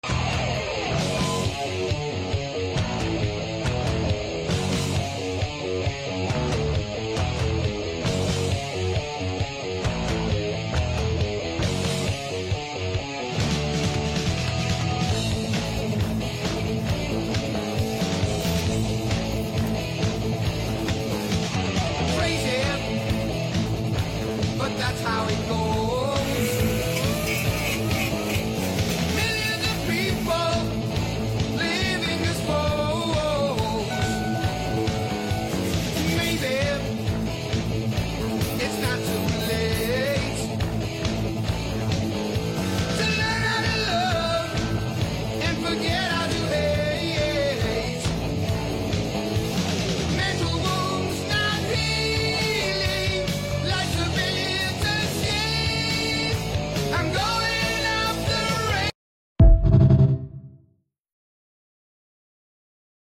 Plastic Repair Sound Effects Free Download